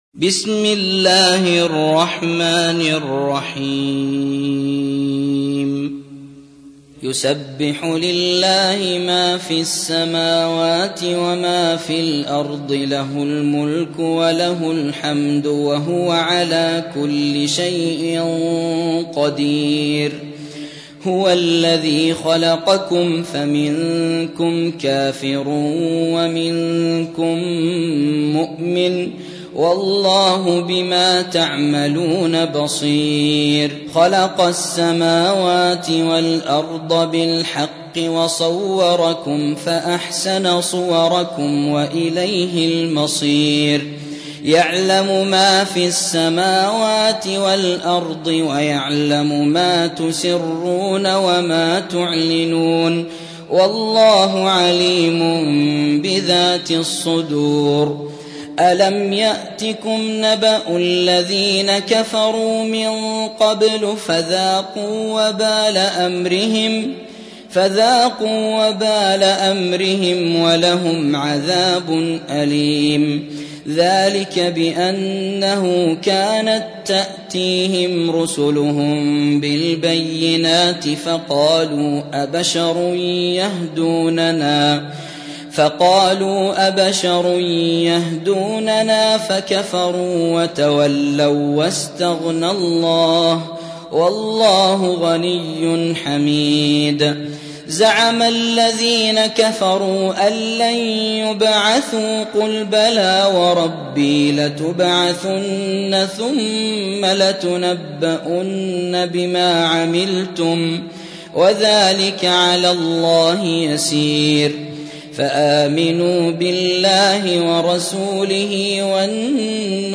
موقع يا حسين : القرآن الكريم 64.